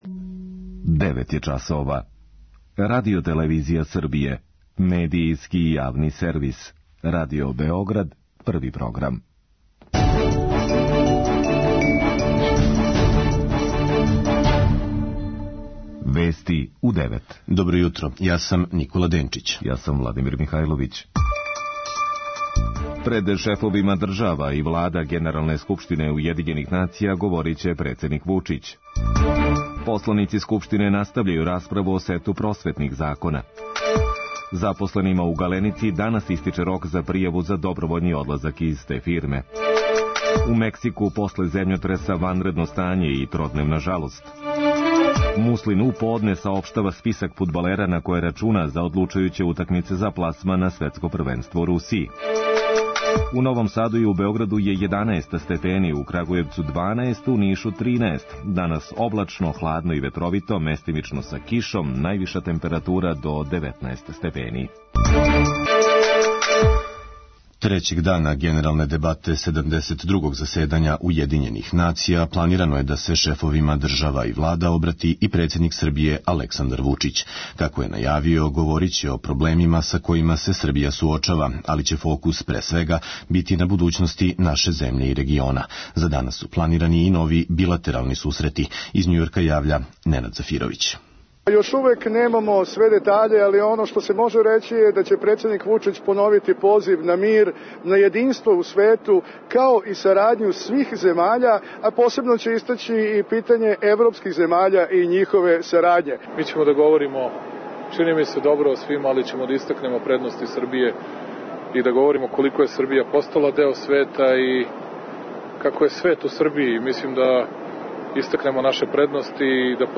преузми : 4.00 MB Вести у 9 Autor: разни аутори Преглед најважнијиx информација из земље из света.